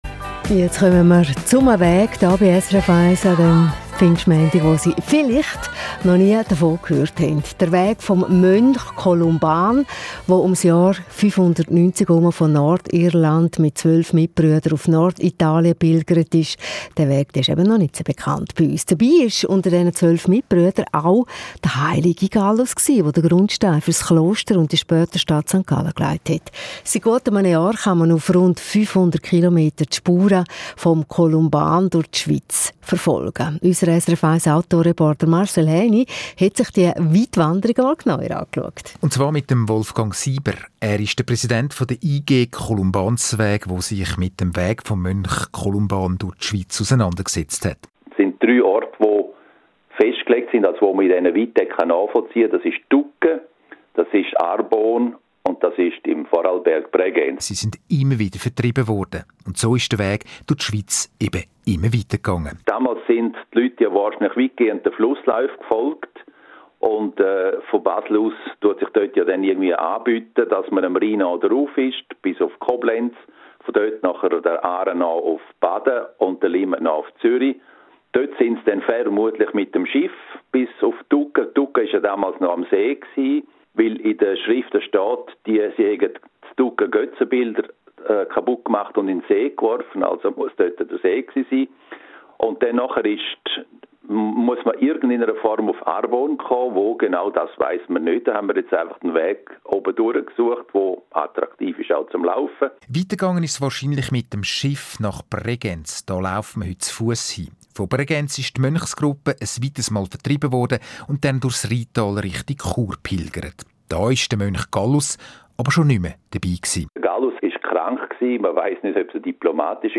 Mai 2021 – Radio SRF 1 – Interview zum Kolumbansweg Schweiz – Kolumbansweg